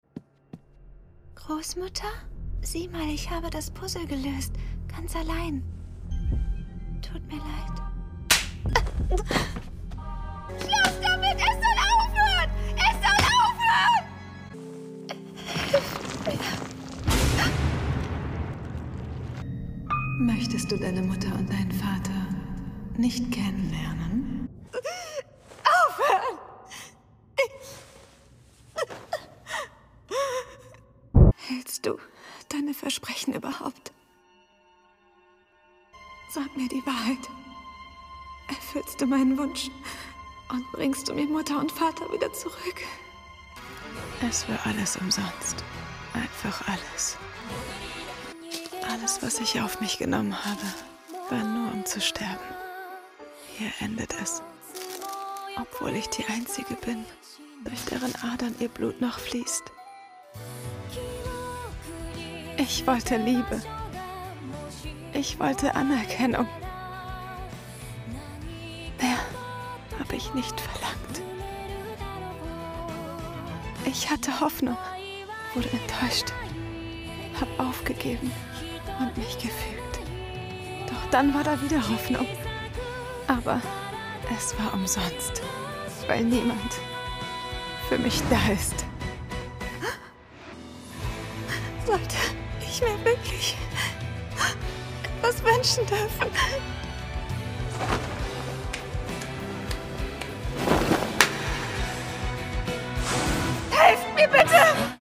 markant
Jung (18-30)
Norddeutsch
Lip-Sync (Synchron)